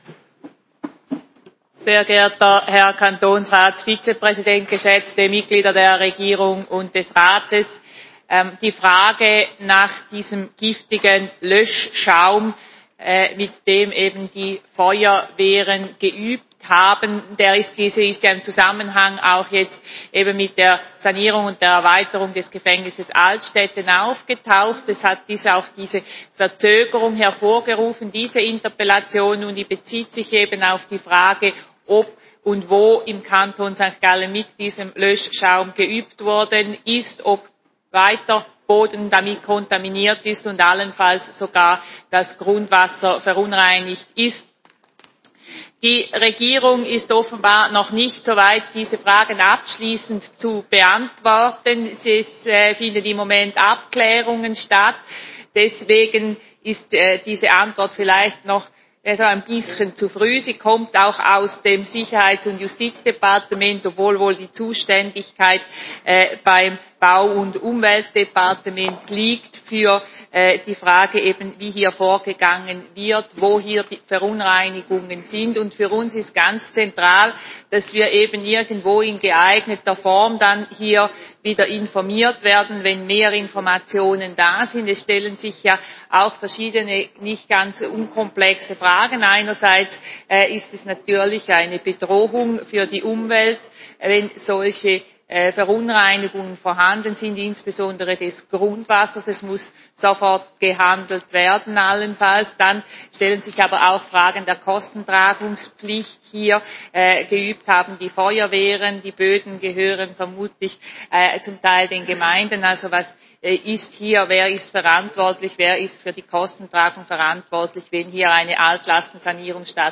Session des Kantonsrates vom 29. November bis 1. Dezember 2021